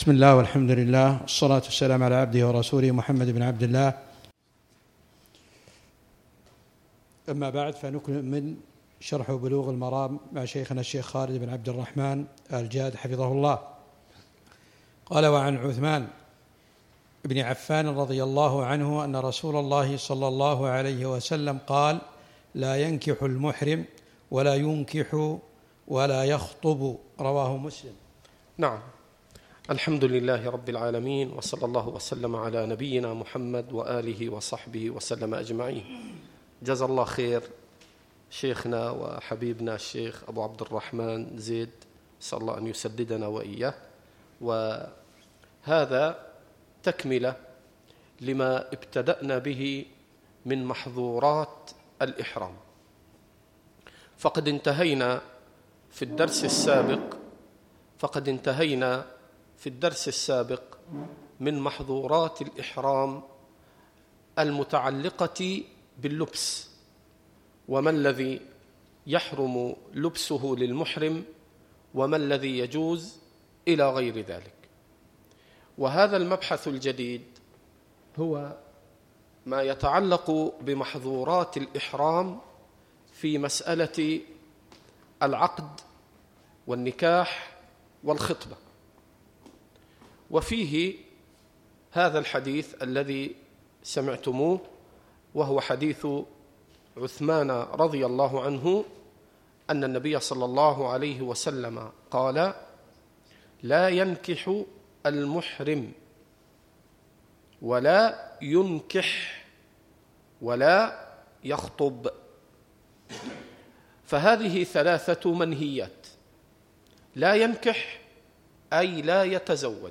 الدرس الثامن - شرح كتاب الحج من بلوغ المرام